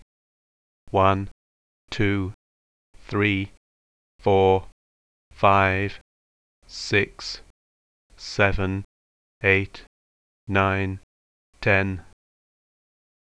They reported that in trying to produce test tapes of regularly spaced English numbers, sequences in which the time intervals were exactly equal tended to sound unequally spaced. It's quite difficult to hear this, but here's an informal demonstration: in the first recording, I recorded the numbers 1 to 10 in what I thought was a regularly-spaced way.